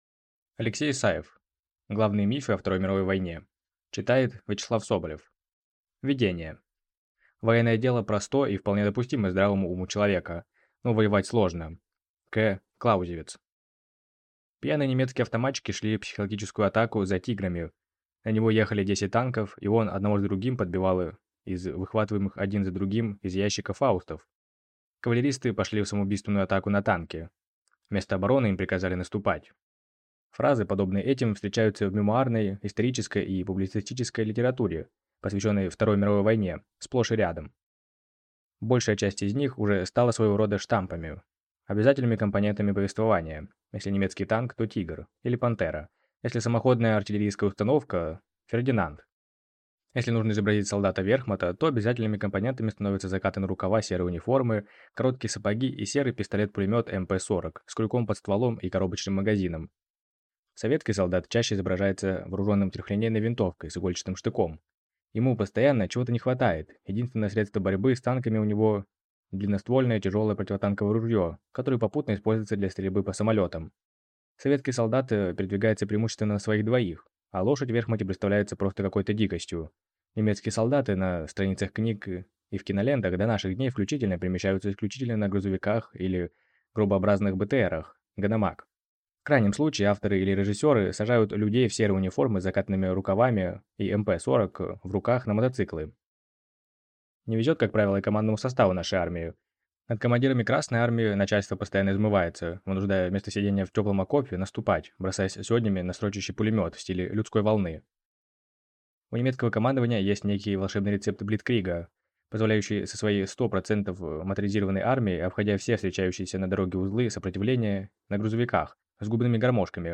Аудиокнига Главные мифы о Второй Мировой | Библиотека аудиокниг